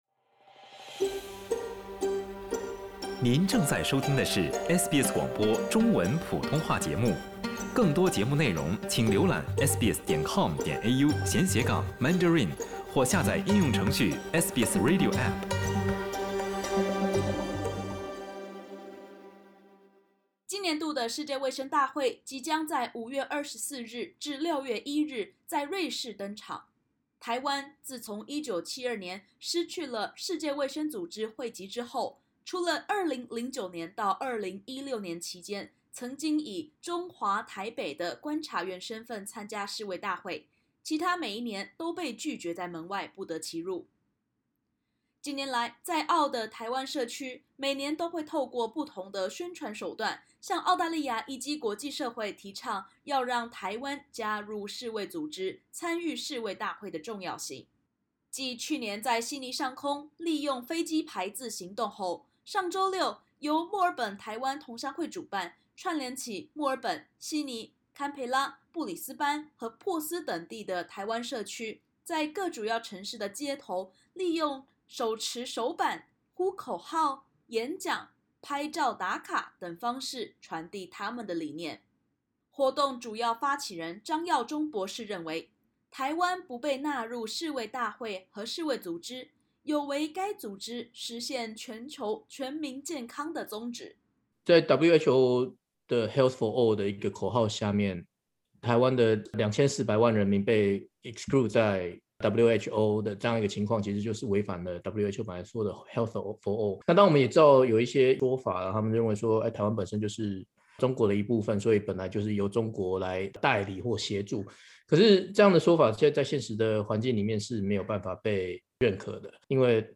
世卫大会（WHA）举办在即，澳洲各主要城市的台湾社区民众走上街头，呼吁澳洲和国际社会支持让台湾加入世卫组织（WHO）。点击首图收听采访音频。